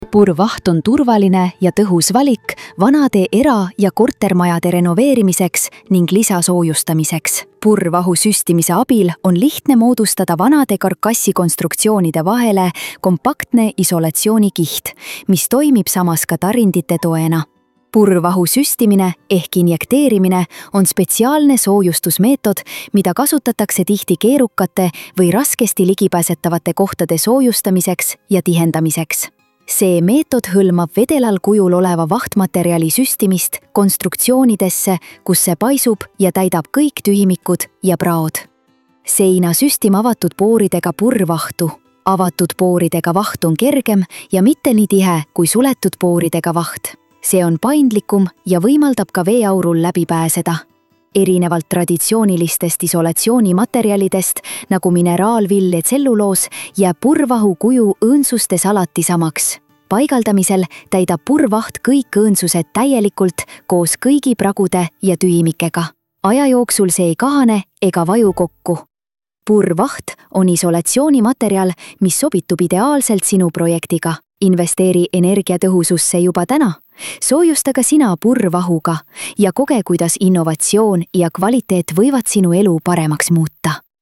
seinte-sustimine-text-to-speech.mp3